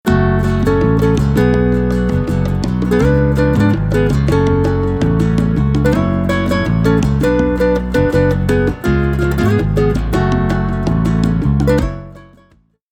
EDIT: В одном файле консоль в байпасе, в другом - работает.